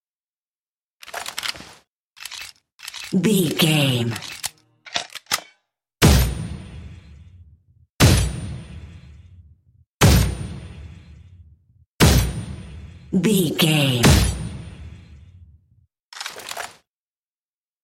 Shotgun Equip, Shells Insert, Reload, Firing and Unequip V01 | VGAME
Filled with 10 sounds(44/16 wav.) of Shotgun Equip, Shells Insert, Reload, Firing(Five single shots) and Unequip, it will be ideal for your films, games, cartoons, animations, battles, war sequences, apps and other contemporary contents.
Sound Effects
Adobe Audition, Zoom h4